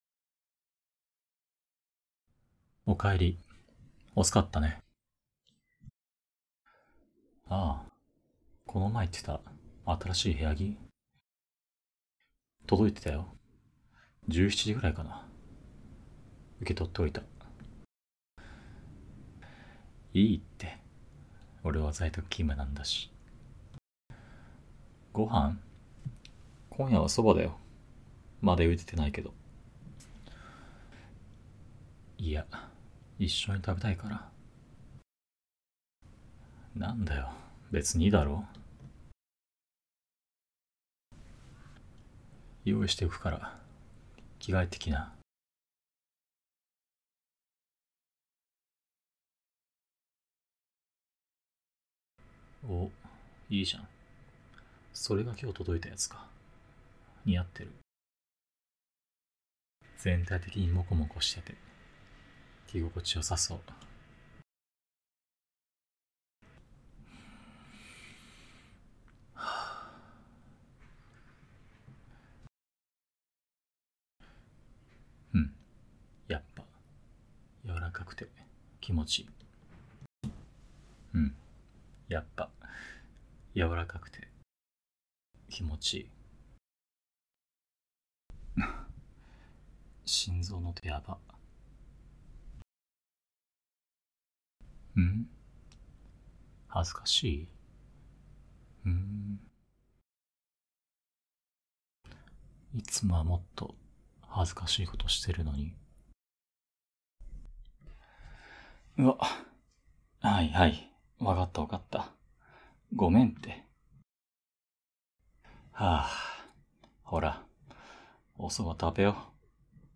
年上彼氏に甘く寝かしつけられるASMR
年上彼氏に甘く寝かしつけられるASMR.mp3